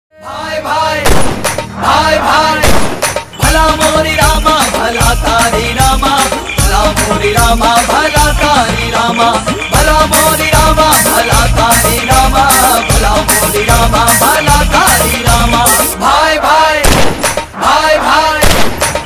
File Type : Navratri dandiya ringtones